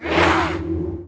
Index of /App/sound/monster/spite_ghost
attack_act_1.wav